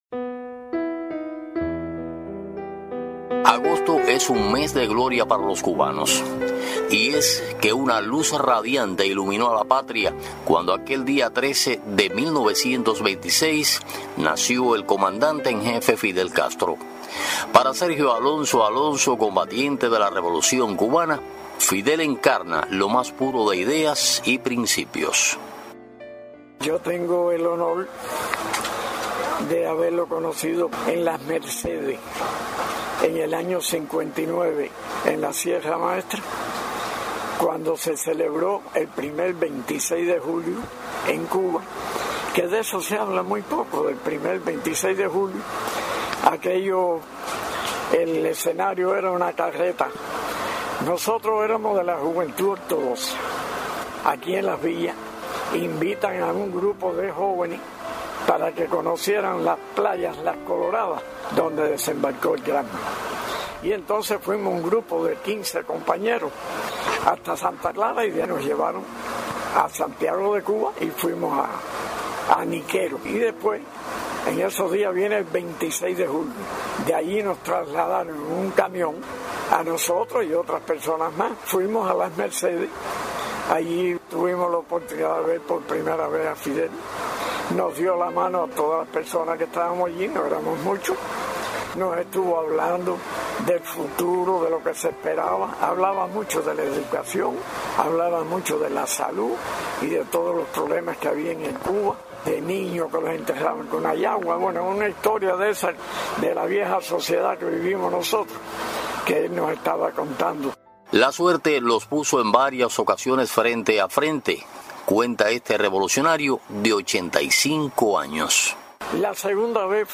🎧 Festival de la Radio en Cienfuegos: Testimonio El recuerdo eterno de Fidel
El Sitio Web de Radio Ciudad del Mar propone escuchar algunos de los trabajos que concursan en el Festival Provincial de la Radio en Cienfuegos.